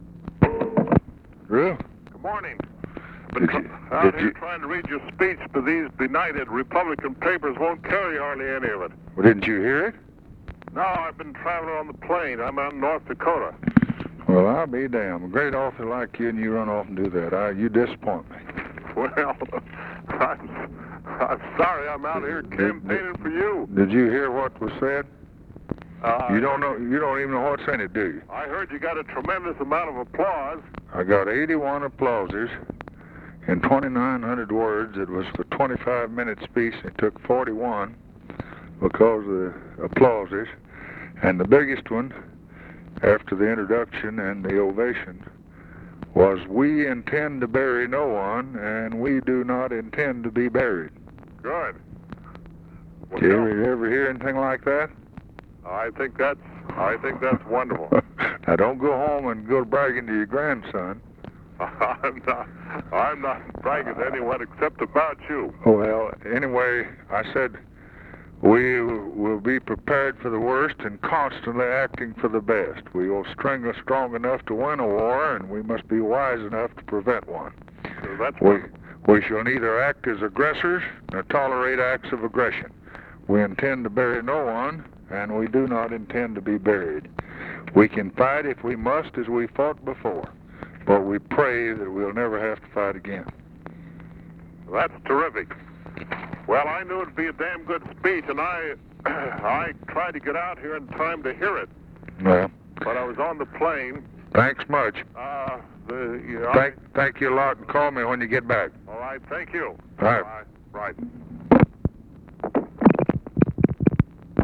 Conversation with DREW PEARSON, January 8, 1964
Secret White House Tapes